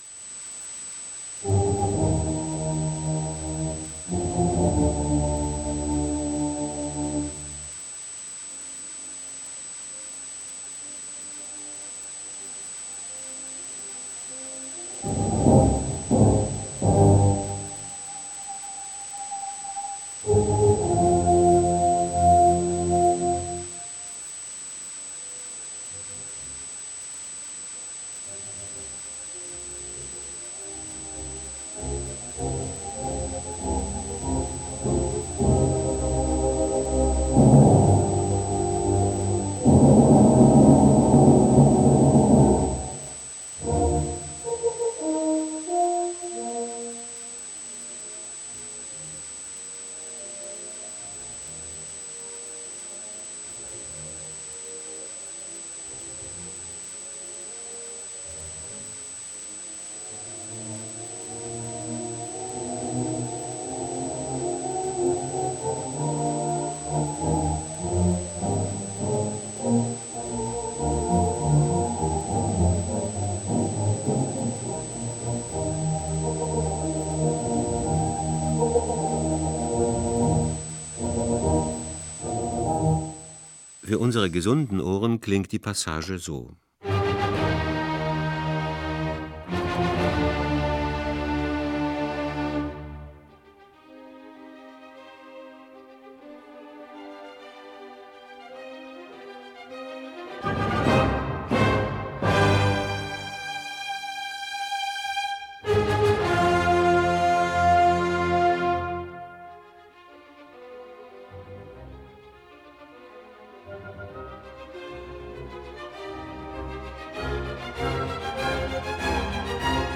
¿Quieres escuchar cómo Beethoven escuchó su Quinta Sinfonía?
Puedes escuchar a través de los oídos de Beethoven aquí.